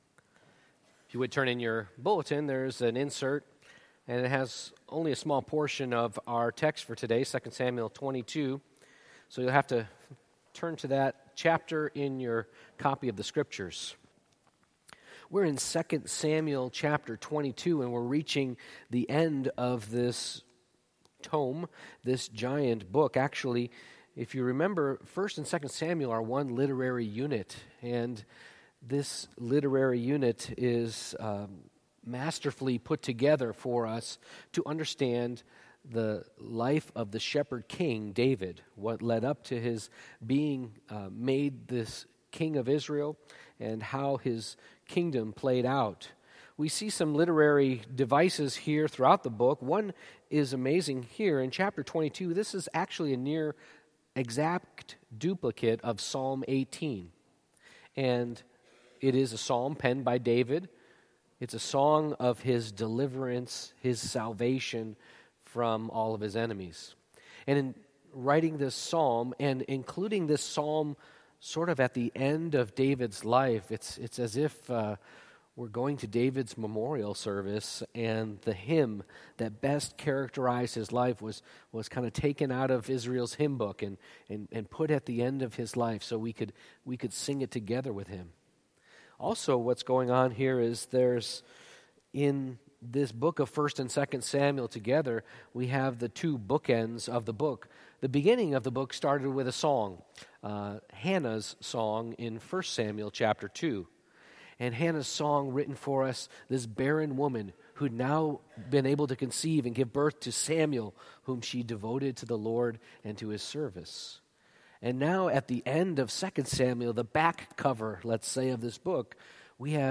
2 Samuel 22:1-51 Service Type: Morning Worship Great salvation through a great Savior produces great praise and devotion.